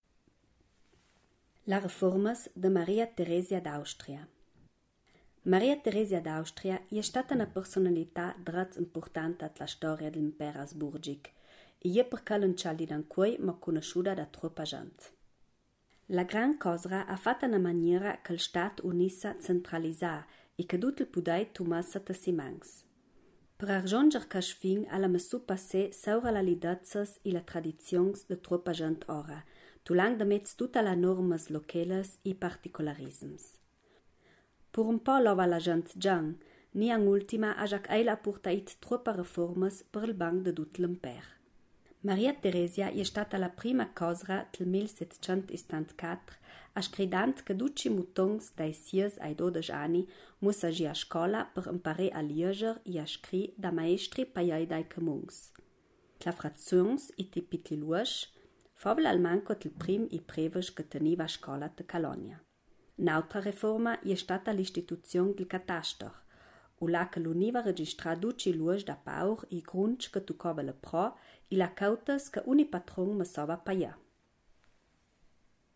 Ladino gardenese